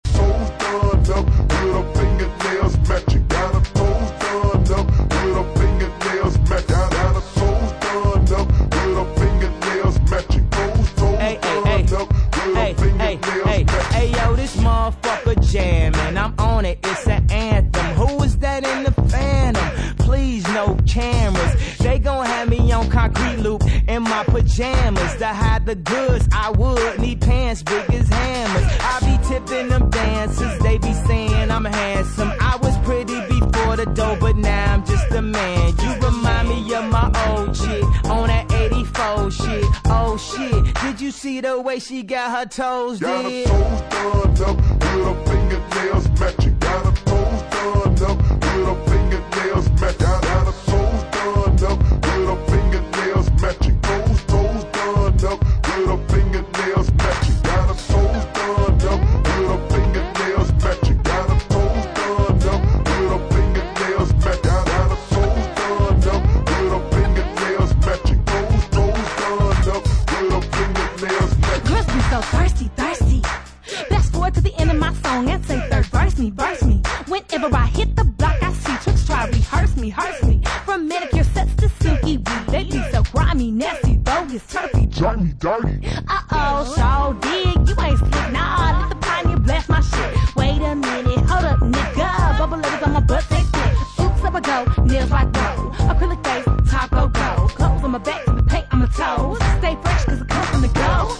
bangin electro booty tracks